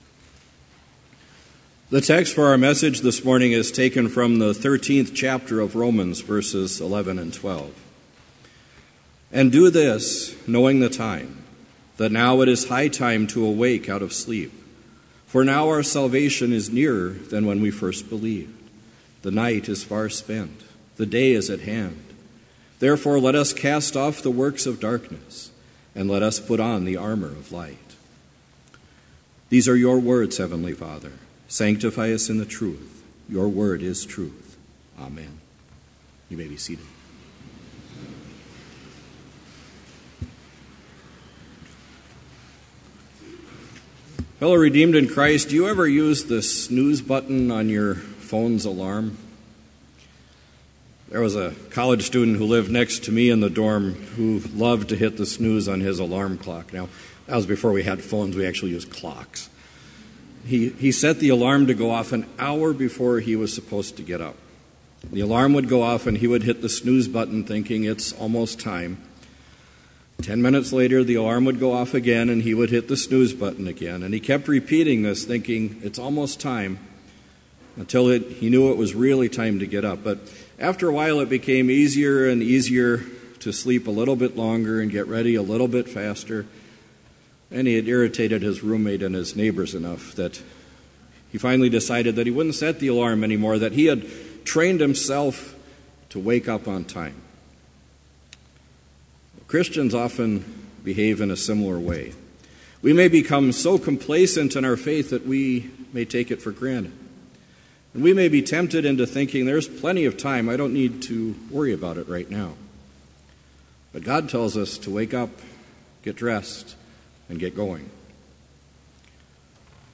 Complete service audio for Chapel - December 5, 2017